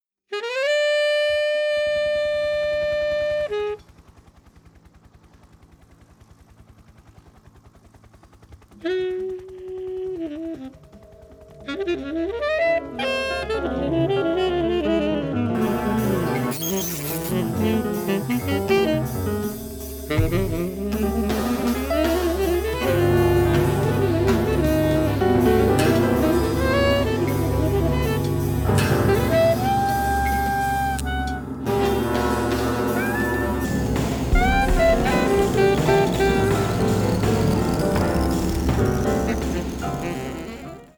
piano, computer
saxophones
Adventurous improvisation is a major feature of the music.